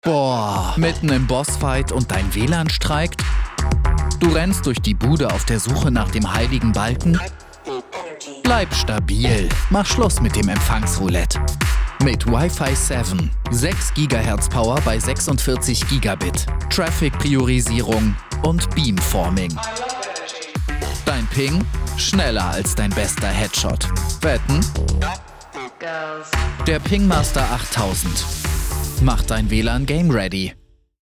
Sprecher und Voice-Over-Künstler mit einer jungen, frischen Stimme – professionell und wandelbar.
Sprechprobe: Werbung (Muttersprache):